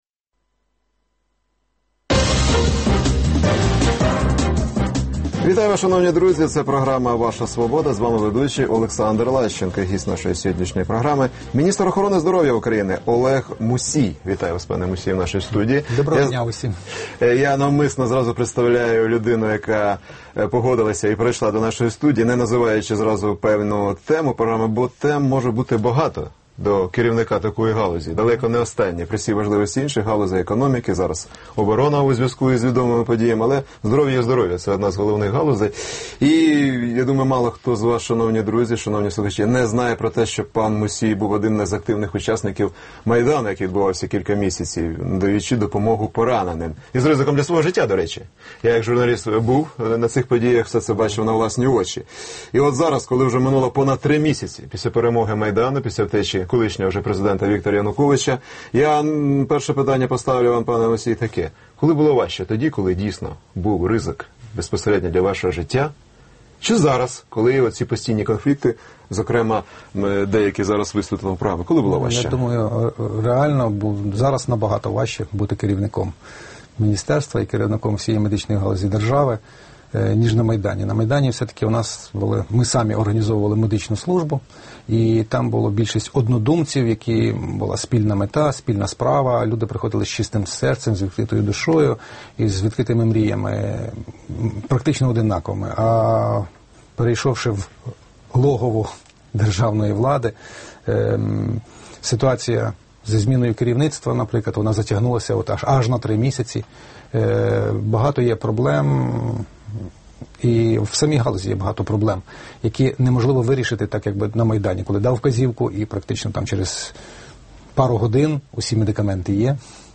Гість: Олег Мусій, міністр охорони здоров’я України